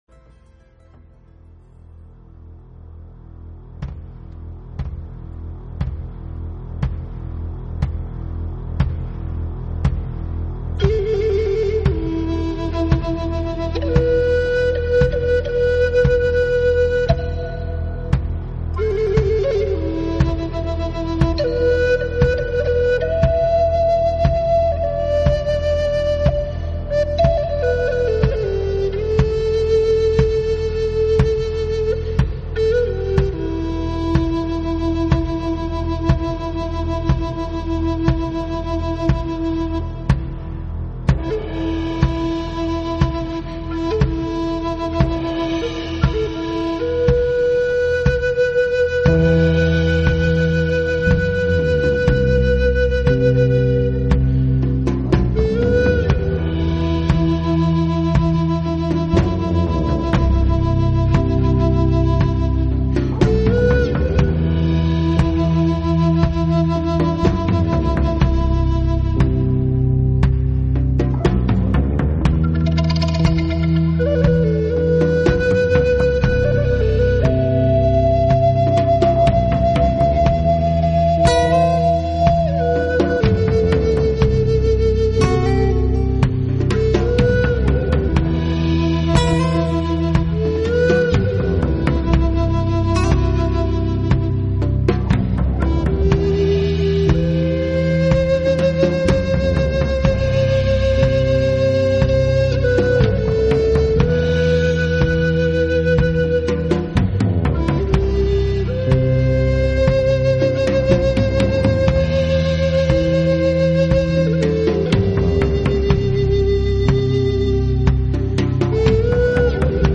与生俱来的抒情嗓音和节奏感，优美的和声和旋律，使这张专